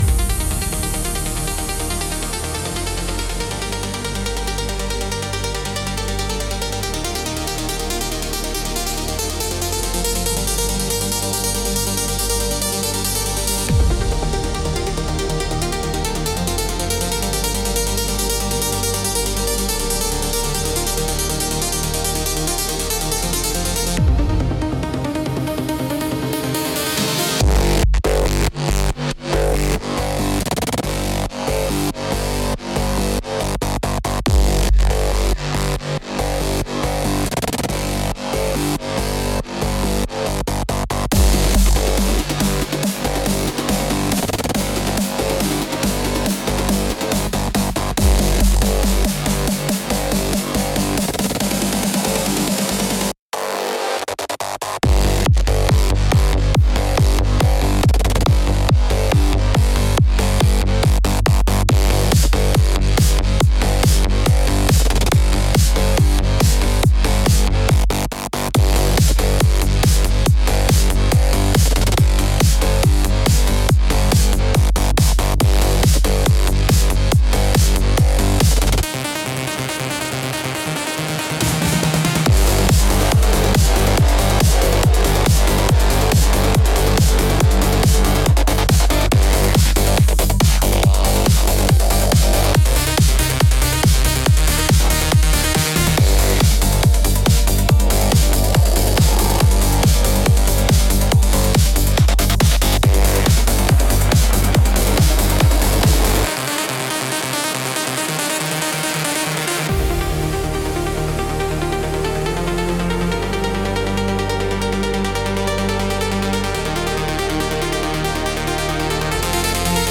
Five high-impact electronic tracks.
Built for late-night energy and controlled impact.